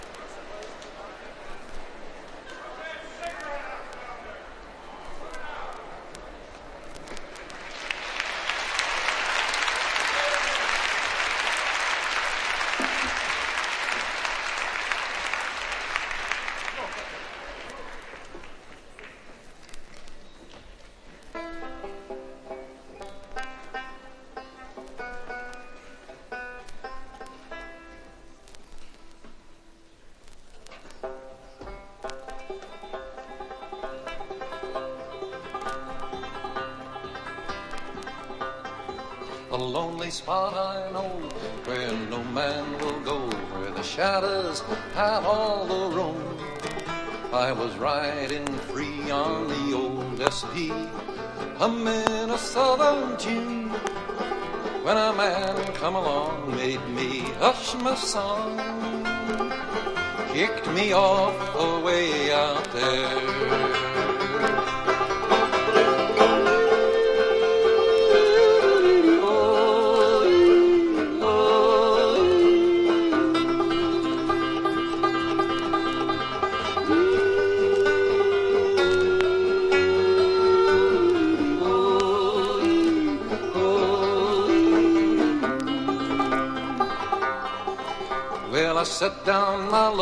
SSW / FOLK